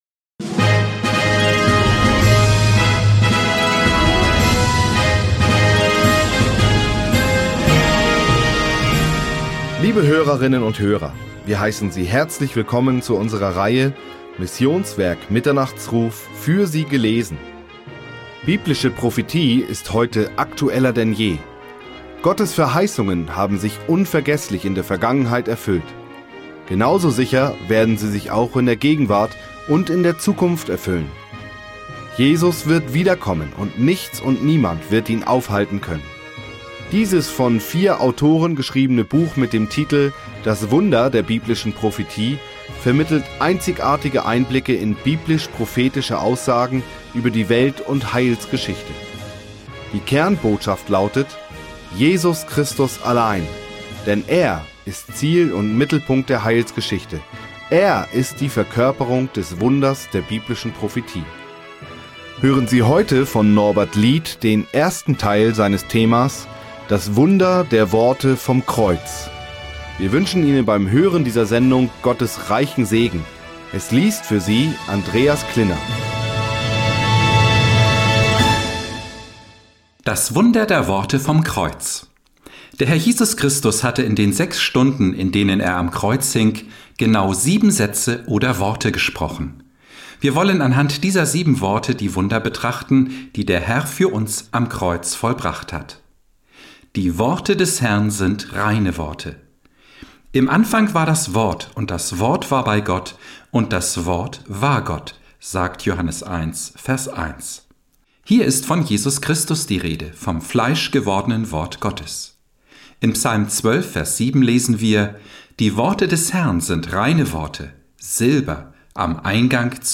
Für Sie gelesen